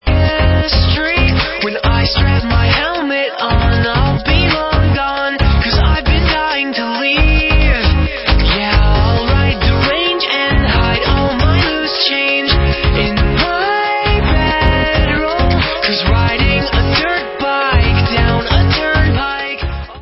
disco, evropský elektropop a osmdesátkový synthpop
Čerstvý, zasněný pop pro každého!